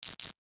NOTIFICATION_Subtle_12_mono.wav